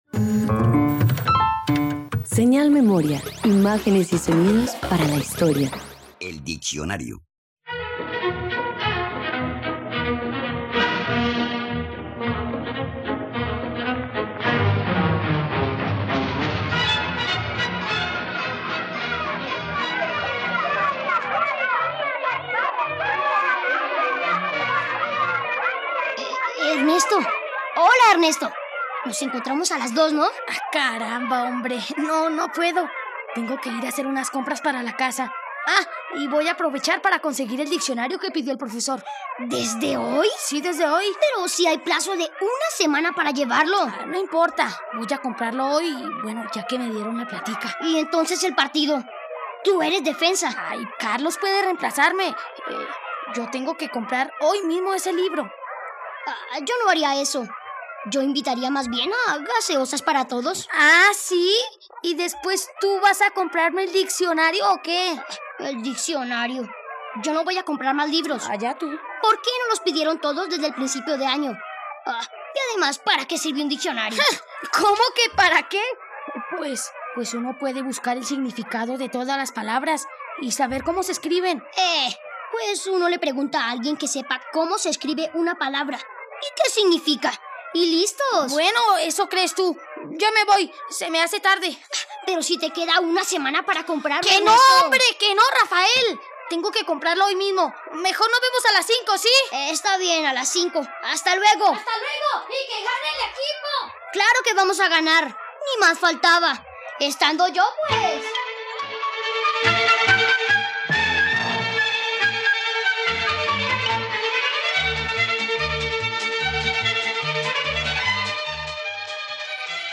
El diccionario - Radioteatro dominical | RTVCPlay